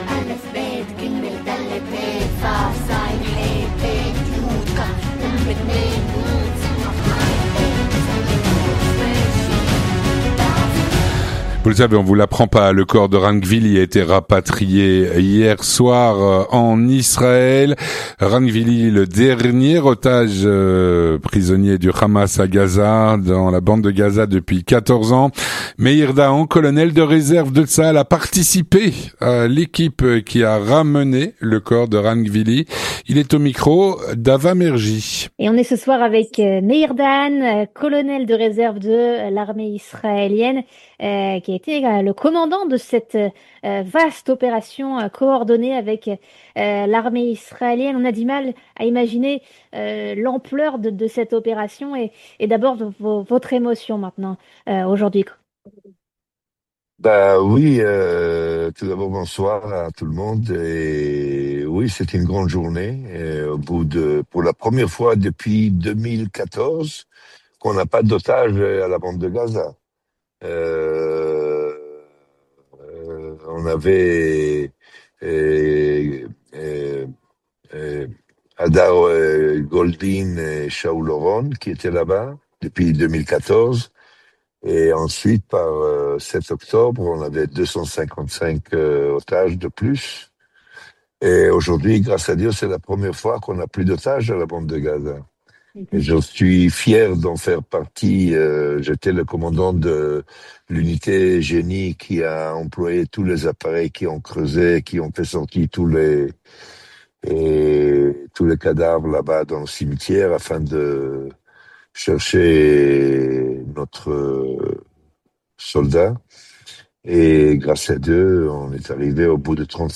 Il témoigne au micro